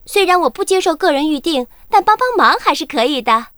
文件 文件历史 文件用途 全域文件用途 Daphne_fw_02.ogg （Ogg Vorbis声音文件，长度0.0秒，0 bps，文件大小：45 KB） 源地址:游戏语音 文件历史 点击某个日期/时间查看对应时刻的文件。